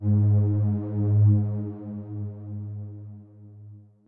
b_basspad_v127l1o3gp.ogg